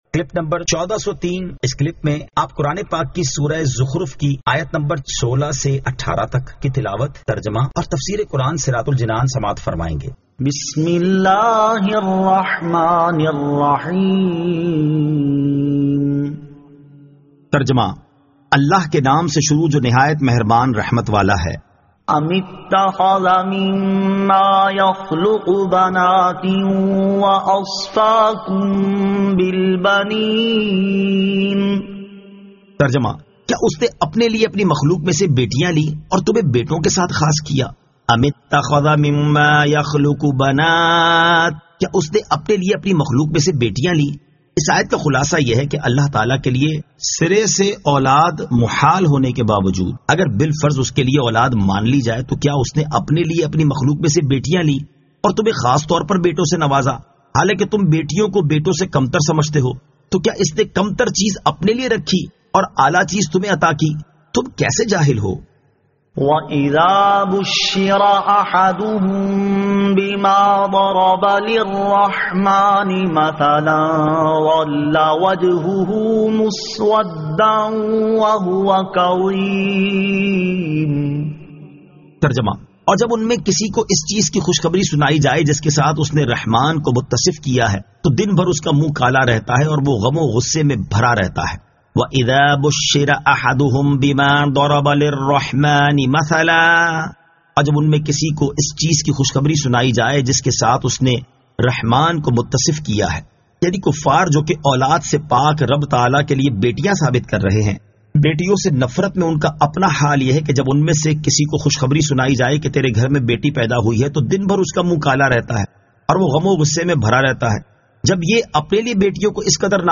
Surah Az-Zukhruf 16 To 18 Tilawat , Tarjama , Tafseer